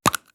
Abrir la caja de un carrete de fotografías